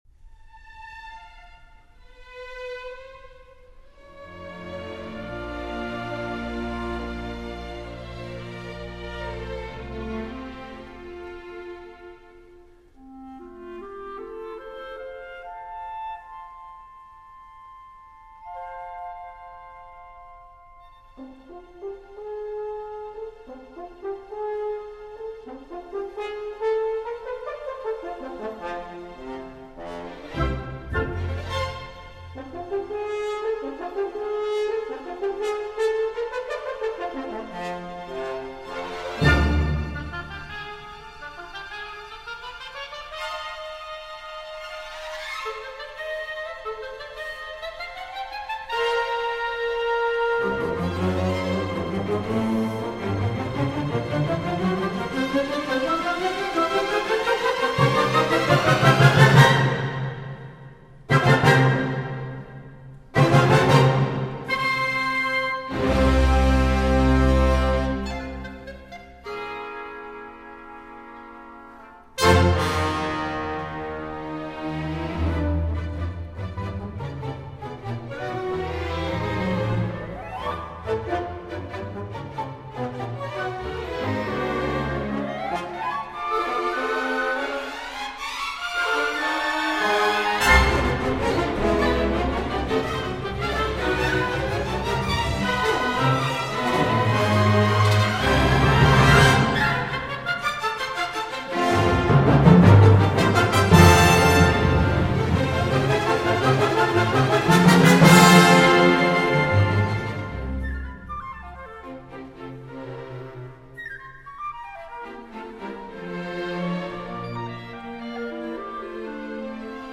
Nel giorno della presentazione della Stagione 2024/2025 dell’ Orchestra Sinfonica di Milano tenutasi presso l’Auditorium di Milano in Largo Mahler, in cui a presentare la programmazione è stato il nuovo Direttore Musicale, Emmanuel Tjeknavorian , proponiamo l’incontro con lui realizzato qualche mese fa, poco tempo dopo la sua nomina.